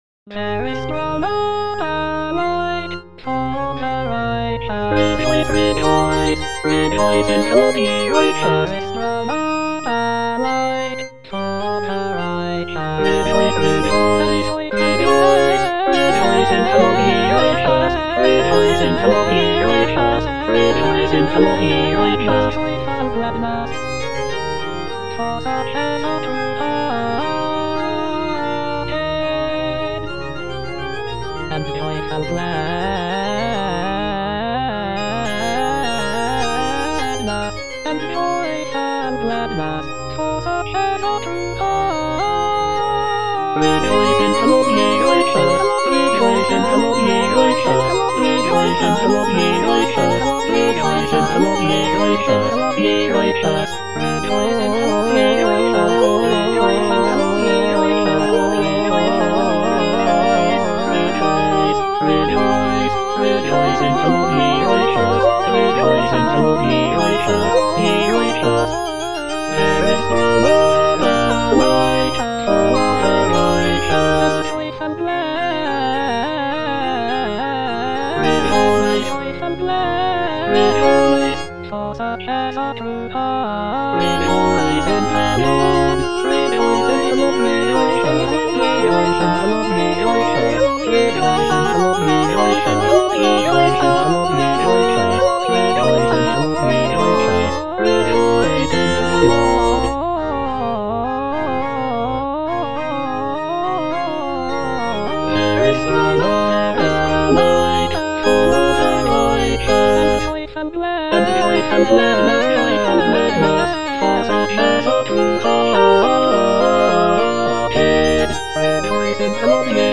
Choralplayer playing O come, let us sing unto the Lord - Chandos anthem no. 8 HWV253 (A = 415 Hz) by G.F. Händel based on the edition CPDL #09622
G.F. HÄNDEL - O COME, LET US SING UNTO THE LORD - CHANDOS ANTHEM NO.8 HWV253 (A = 415 Hz) There is sprung up a light (All voices) Ads stop: auto-stop
It is a joyful and celebratory piece, with uplifting melodies and intricate harmonies.
The use of a lower tuning of A=415 Hz gives the music a warmer and more resonant sound compared to the standard tuning of A=440 Hz.